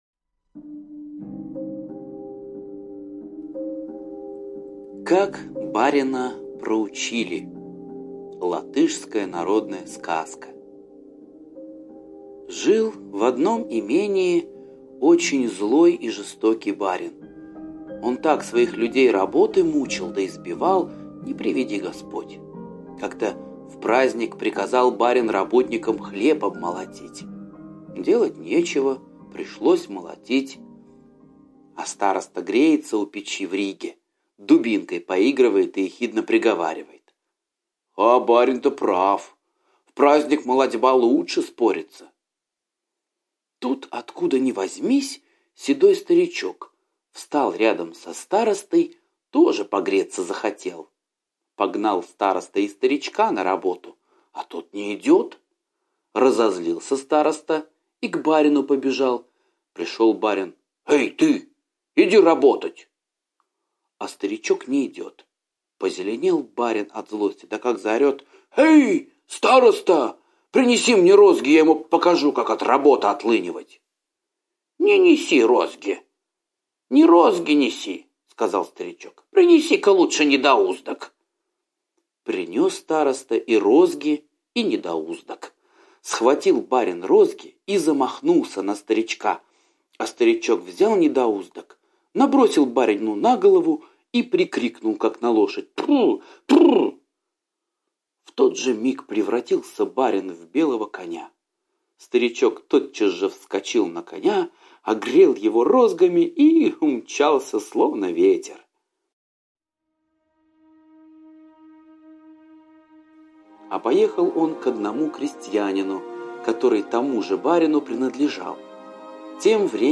Как барина проучили - латышская аудиосказка - слушать